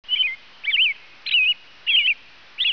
Bird.wav